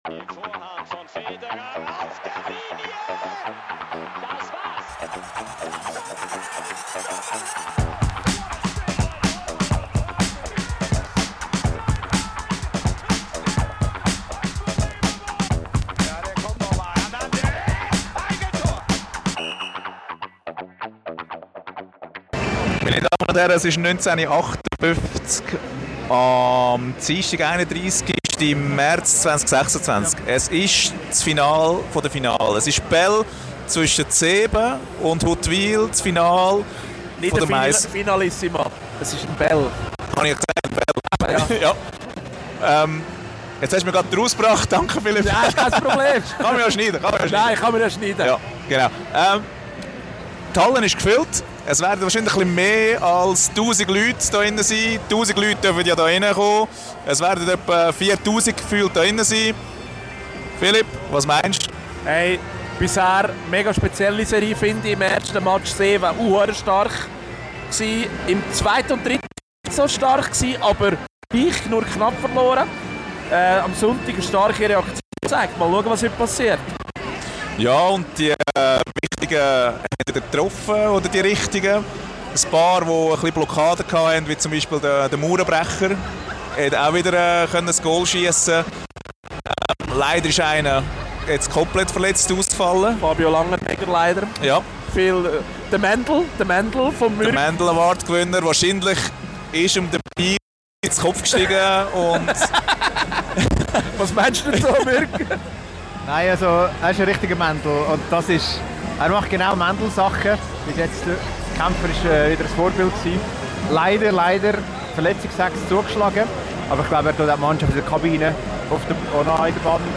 Wir melden uns direkt aus dem Stadion – mit mehreren Liveschaltungen und Meinungen zum Spiel. Bier in der Hand, Stimme weg und mittendrin im Final-Fieber.
Wir haben geschrien, gefeiert und einfach nur genossen.